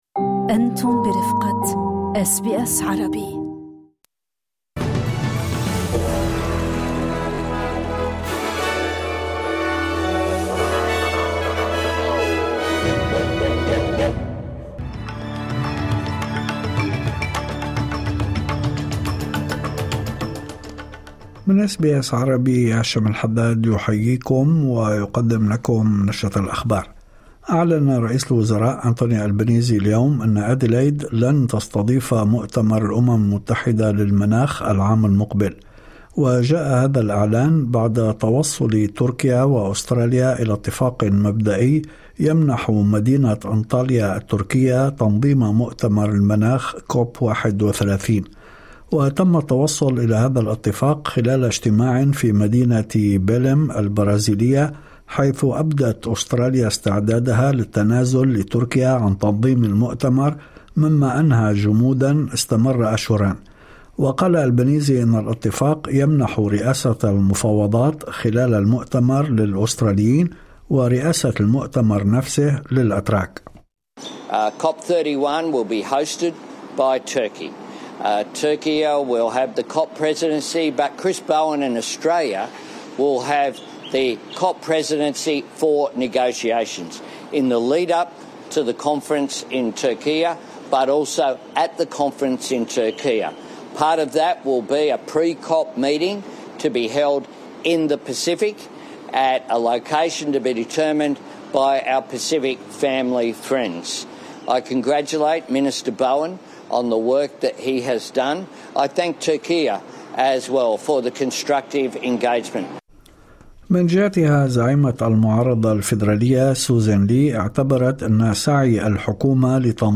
نشرة أخبار الظهيرة 20/11/2025